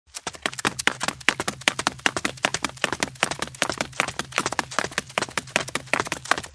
'Title=pas_beton_course_2'
B_MARCHE.mp3